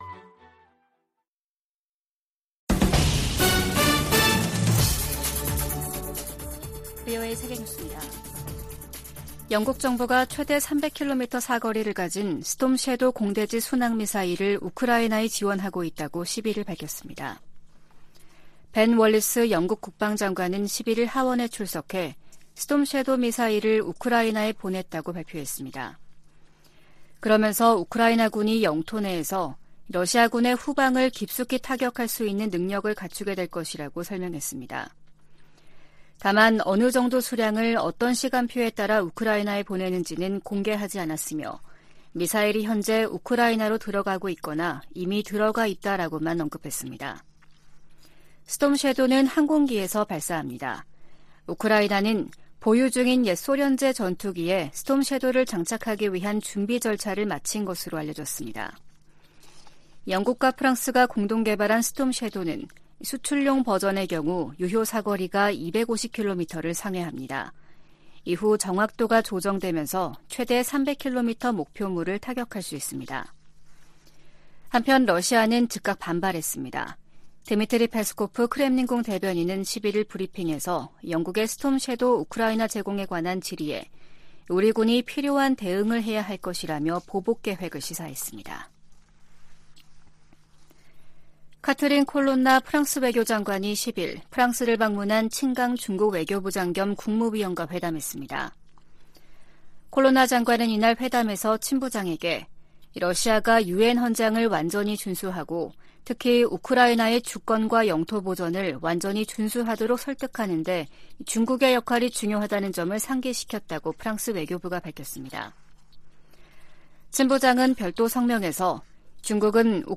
VOA 한국어 아침 뉴스 프로그램 '워싱턴 뉴스 광장' 2023년 5월 12일 방송입니다. 북한이 사이버 활동으로 미사일 자금 절반을 충당하고 있다고 백악관 고위 관리가 말했습니다. 미한 동맹이 안보 위주에서 국제 도전 과제에 함께 대응하는 관계로 발전했다고 미 국무부가 평가했습니다. 미 국방부가 미한일 3국의 북한 미사일 정보 실시간 공유를 위해 두 나라와 협력하고 있다고 확인했습니다.